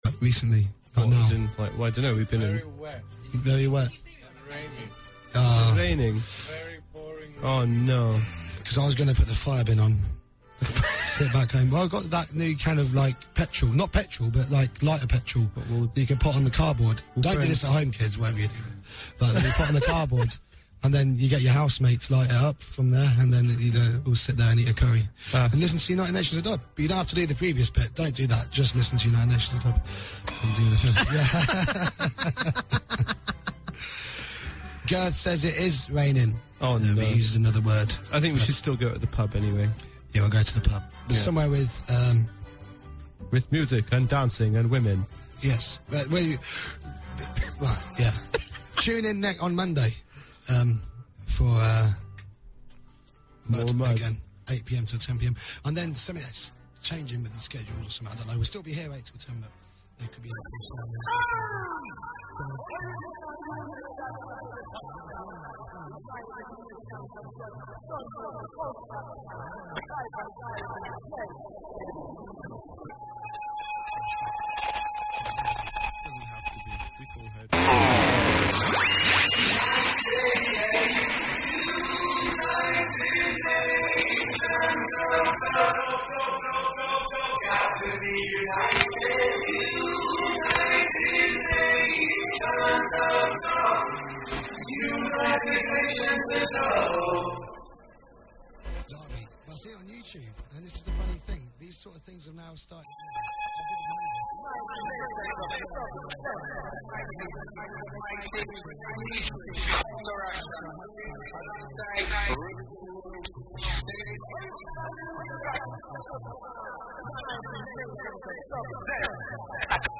Strictly UK Dub vinyl selection! 1.